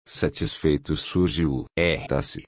To get the longer start to êxtase I added the primary emphasis mark to the IPA in the second and third examples.